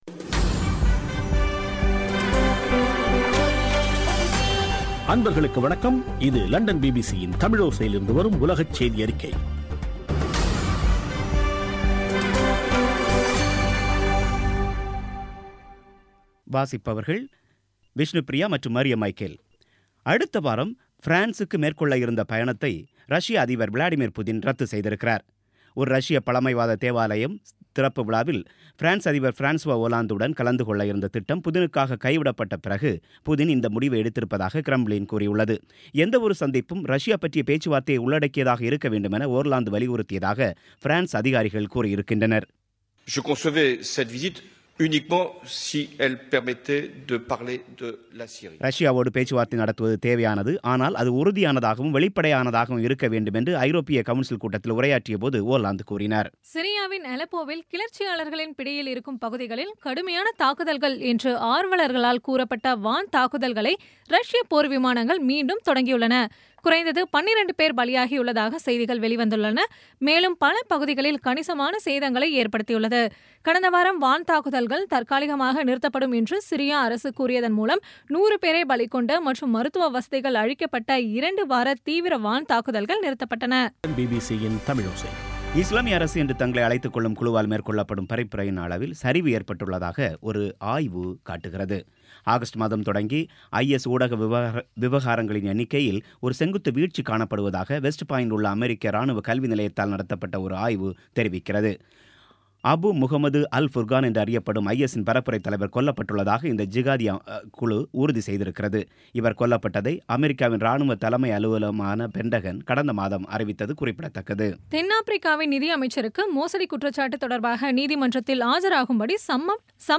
இன்றைய (அக்டோபர் 11ம் தேதி ) பிபிசி தமிழோசை செய்தியறிக்கை